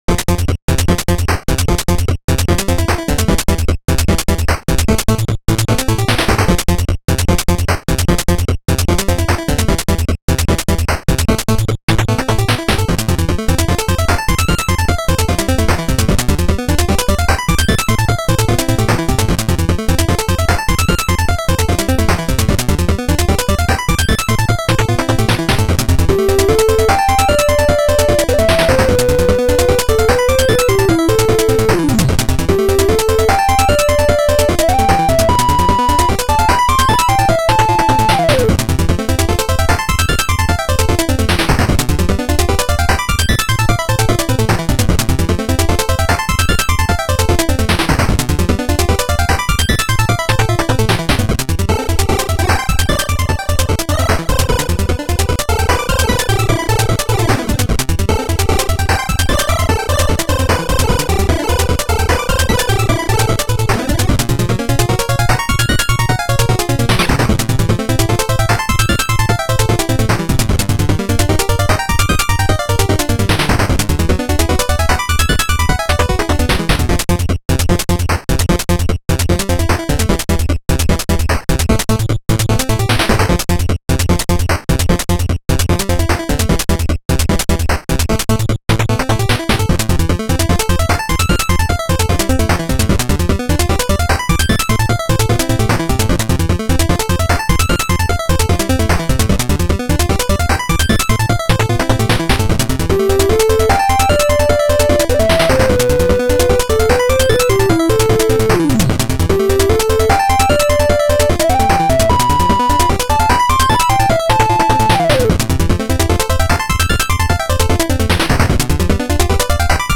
A Legit NES Famitracker Track.
Good for a jumpin' n' shootin' level.